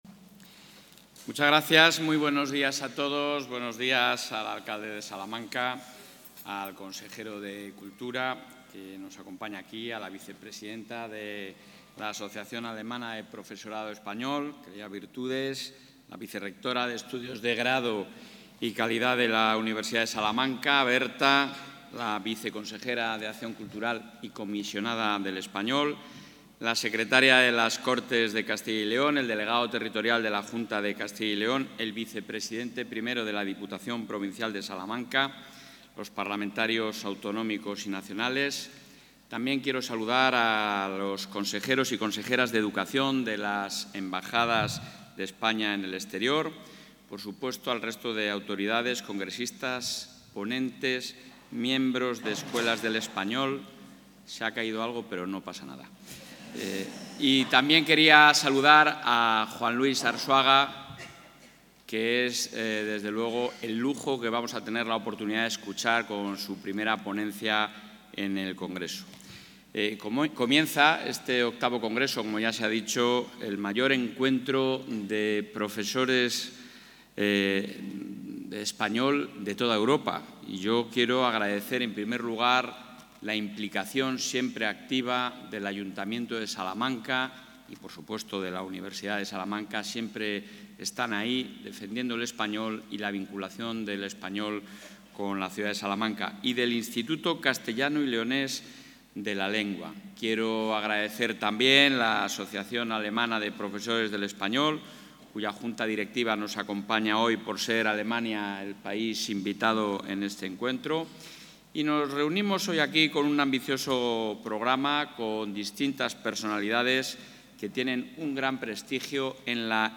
El presidente de la Junta de Castilla y León, Alfonso Fernández Mañueco, ha inaugurado hoy en Salamanca el VIII Congreso...
Intervención del presidente de la Junta.